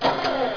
sound_rmmachines.wav